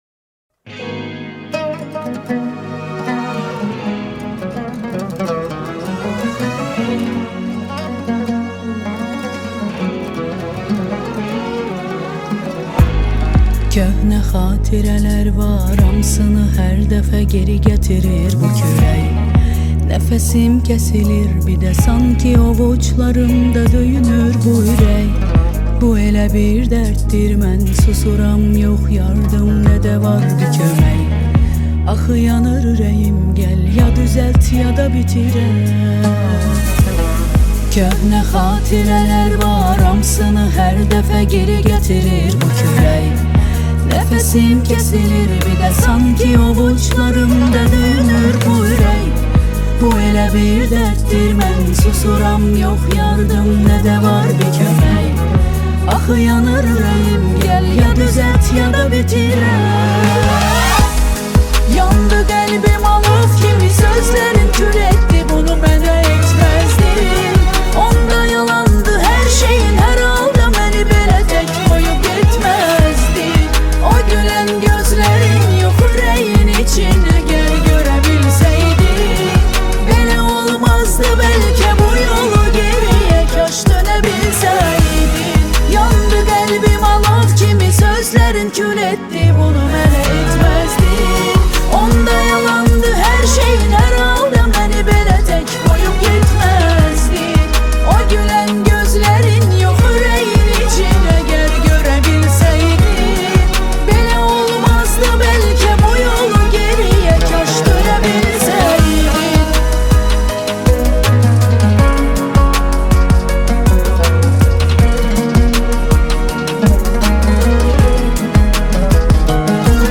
Azeri Muzik